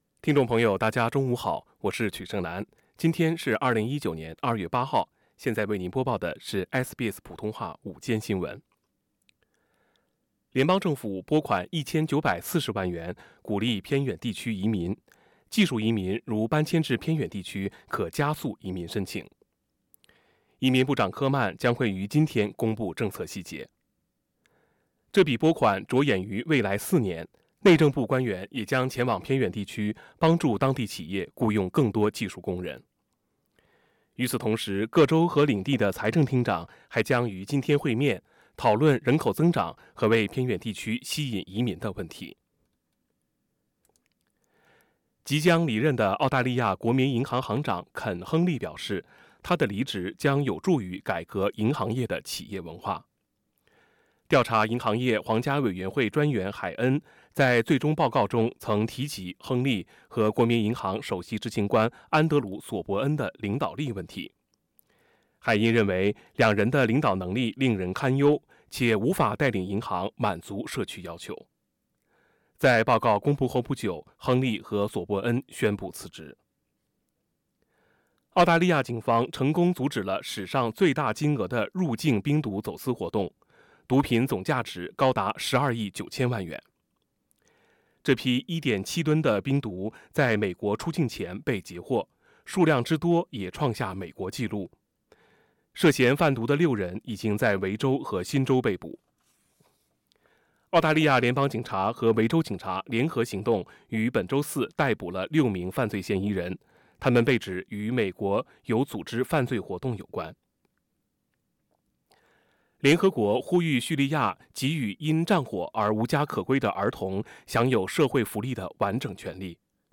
midday_news_feb_8.mp3